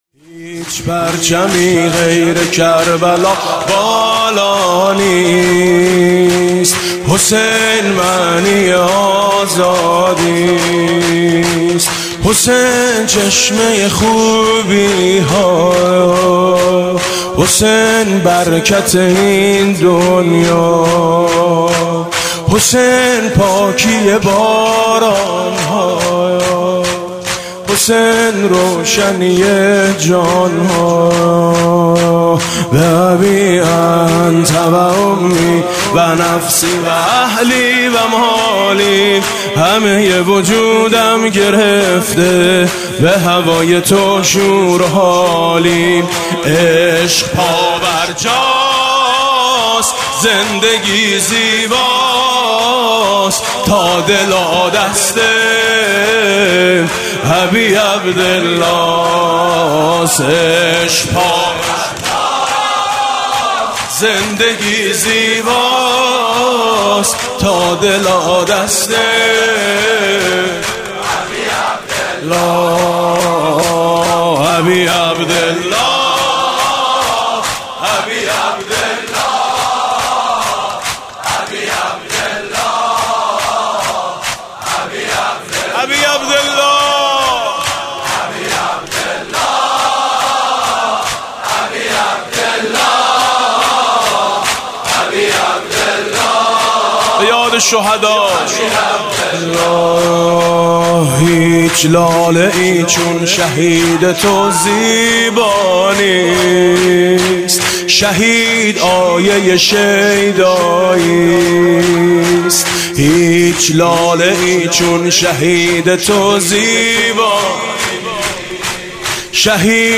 اربعین 98